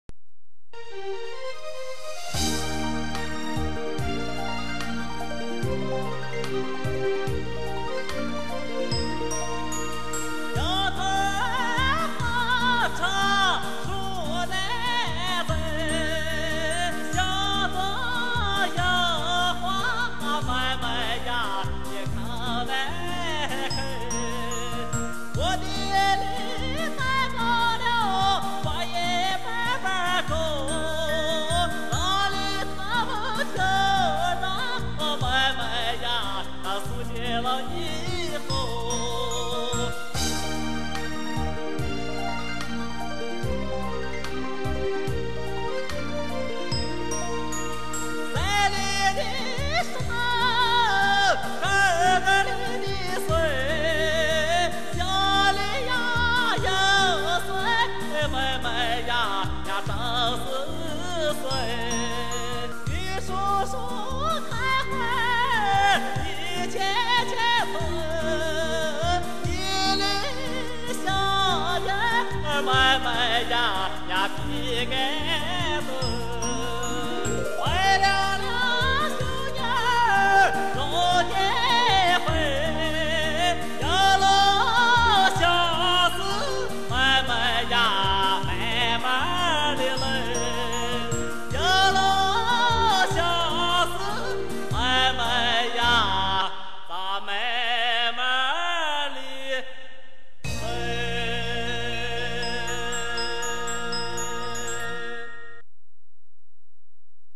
[2007-4-8]山西左权羊倌歌手石占明纯朴的演唱山西民歌『有了心思慢慢来』 引用: [mjh1]《 有了心思慢慢来》[/mjh1] 山西左权民歌 演唱 石占明 樱桃好吃树难栽， 心中有话妹妹呀口难开。